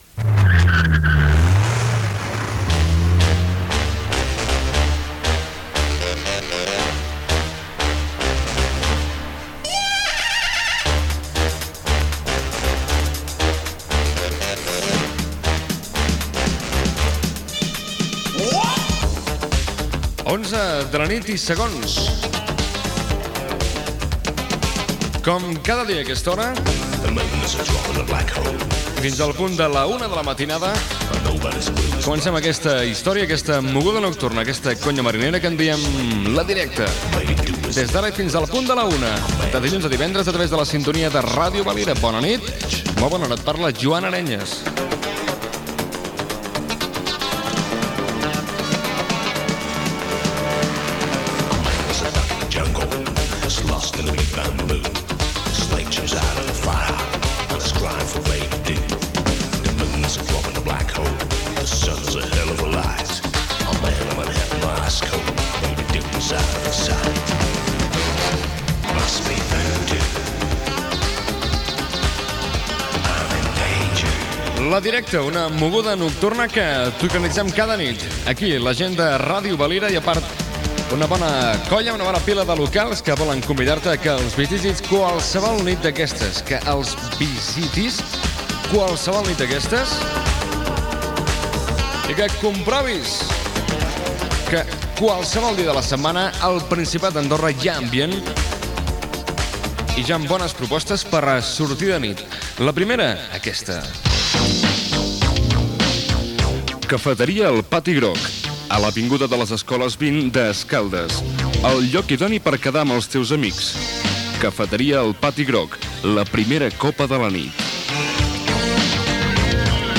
Tema musical, hora, presentació inicial del programa, publicitat, hora, publicitat i tema musical
Musical